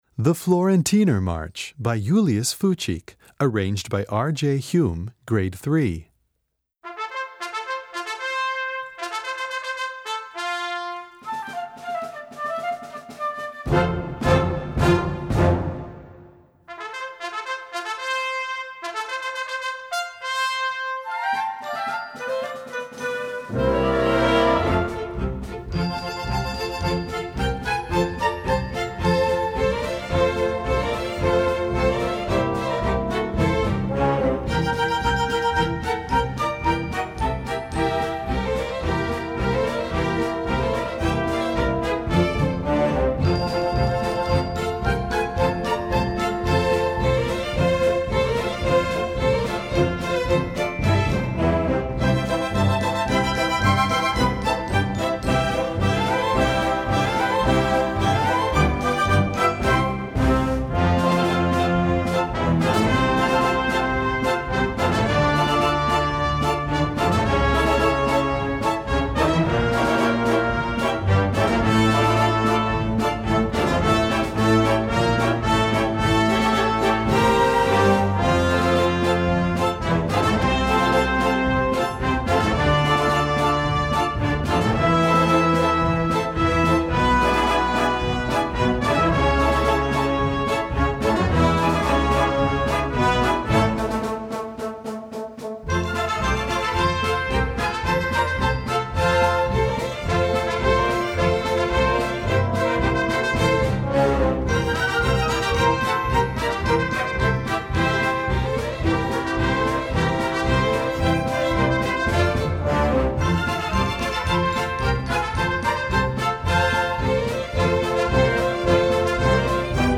Voicing: Full Orchestra